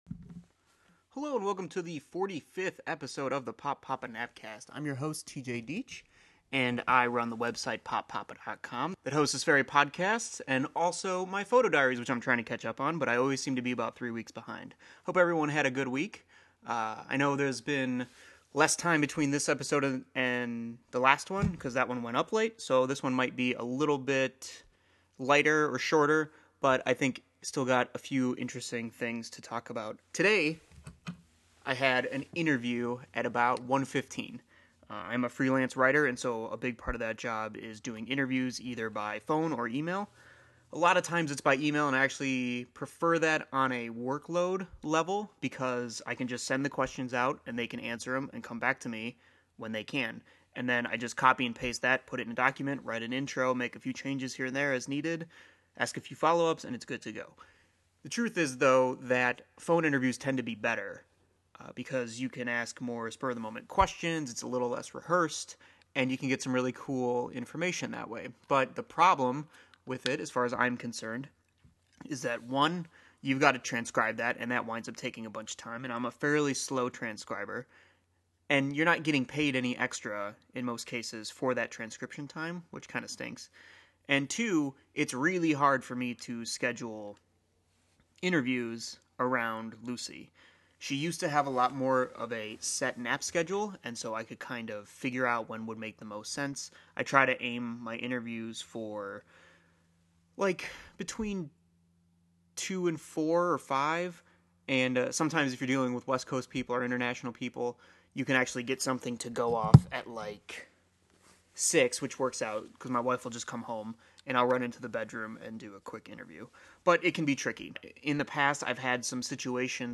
Sorry about the difference in sound around the 22 minute mark. I lost about 10 minutes of the original recording and had to get back in there and do a replacement track.